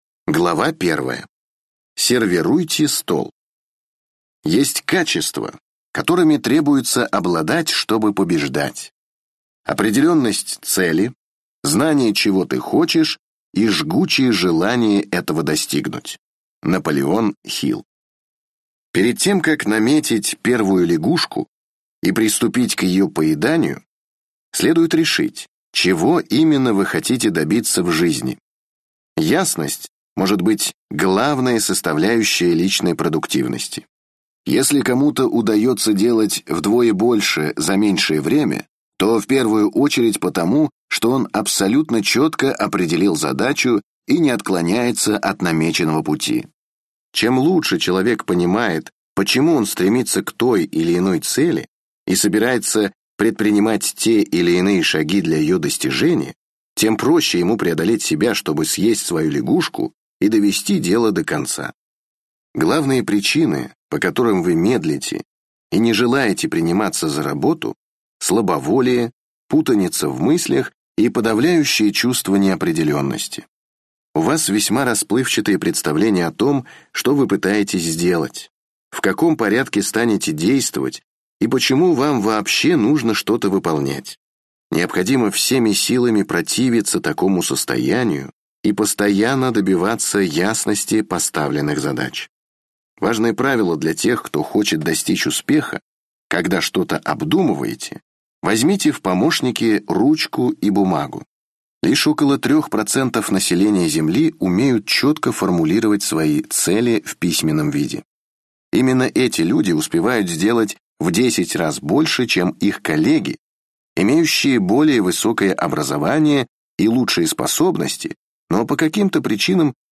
Аудиокнига Выйди из зоны комфорта. Измени свою жизнь | Библиотека аудиокниг